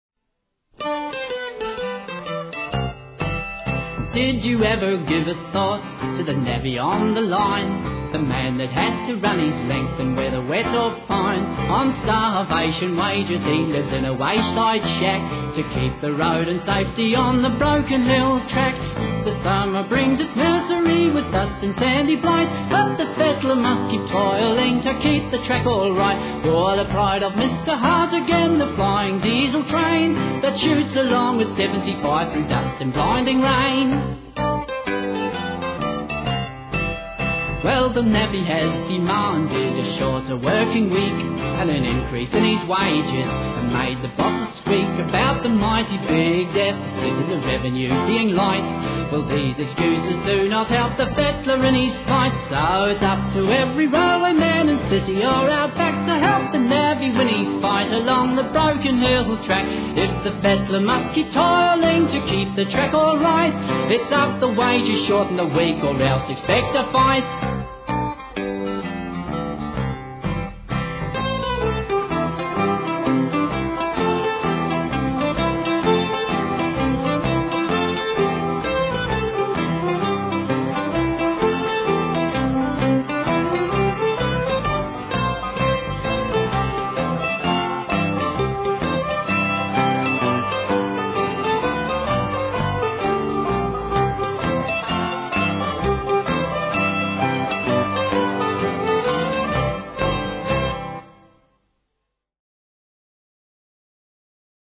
vocals.